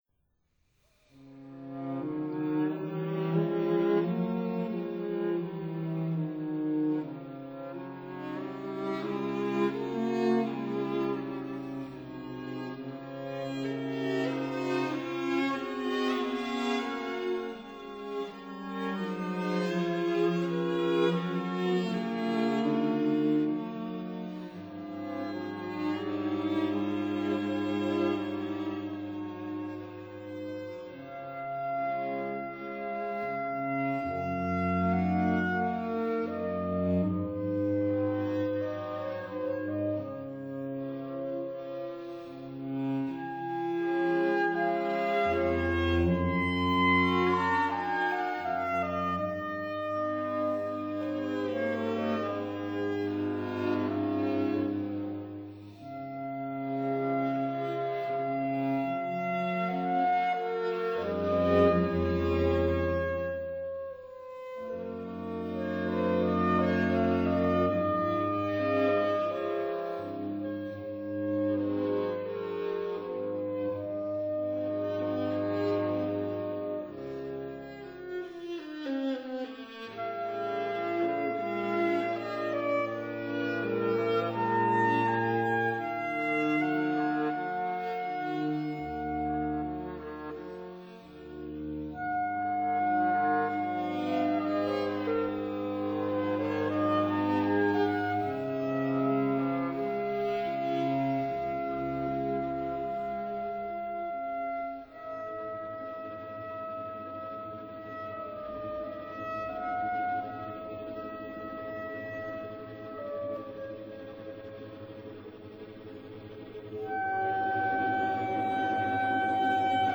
Clarinet Quintet No. 3 in E-flat major
violin
viola
cello